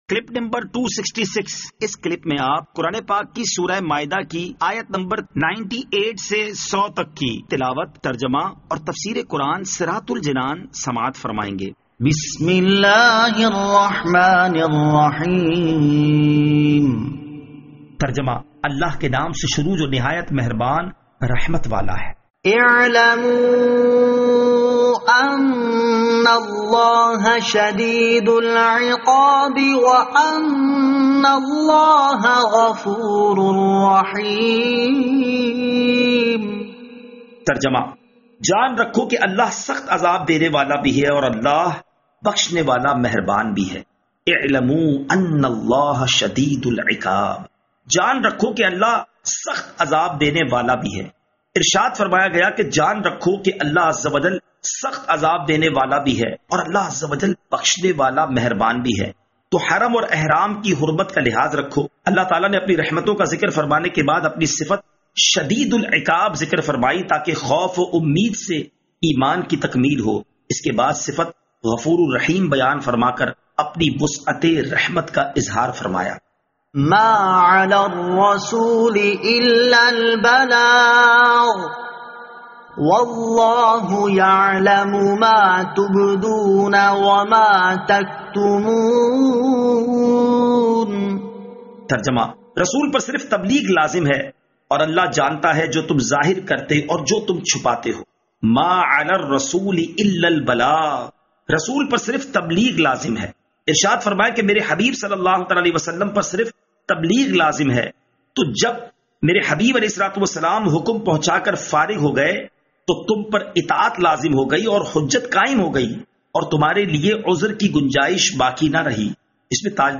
Surah Al-Maidah Ayat 98 To 100 Tilawat , Tarjama , Tafseer